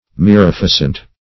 Mirificent \Mi*rif"i*cent\, a.